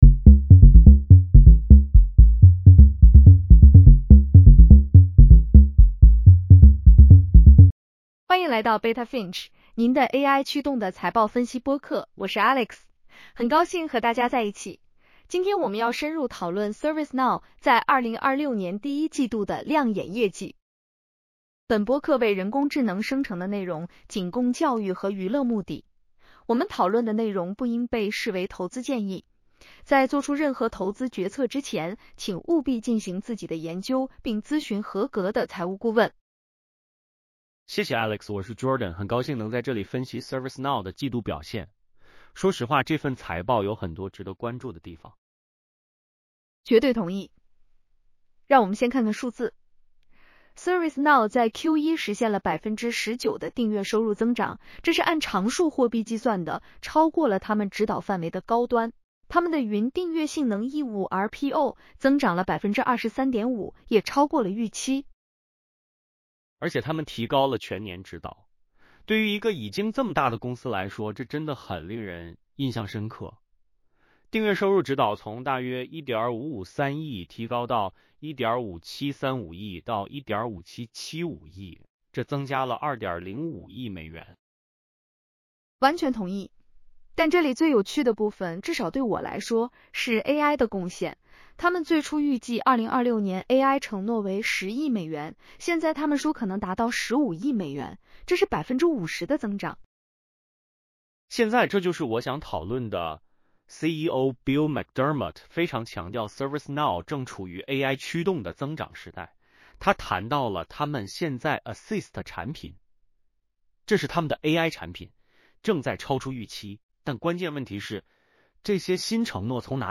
本播客为人工智能生成的内容，仅供教育和娱乐目的。